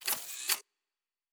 Weapon 13 Reload 2.wav